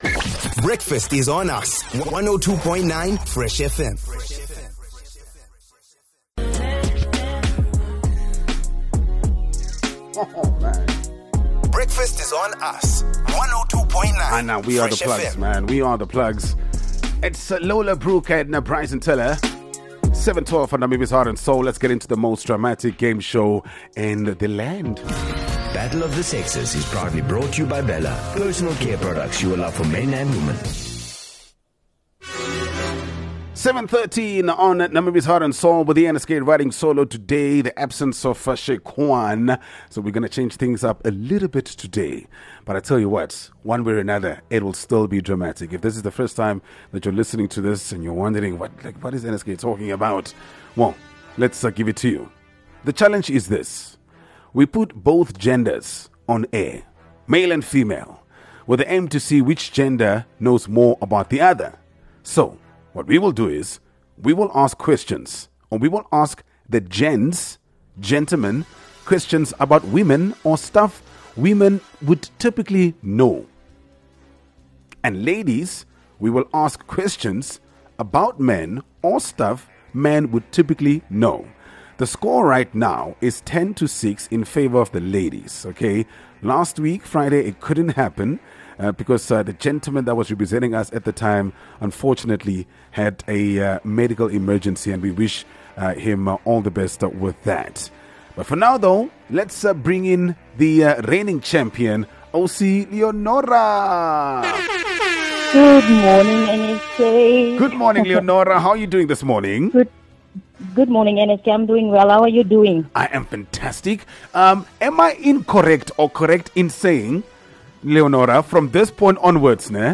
Battle of the Sexes is probably the most dramatic game show on Namibian radio. This is the bit where we have both genders on air with the aim to see which knows more about the other. So we will ask the gents questions about the ladies and ladies…we will ask questions about the gents!